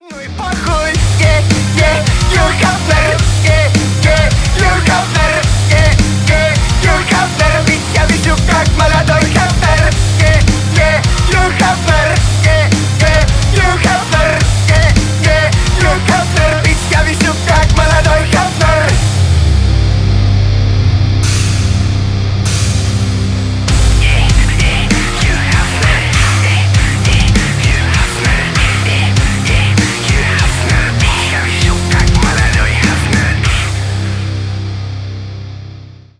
Рок Металл # Ремикс
громкие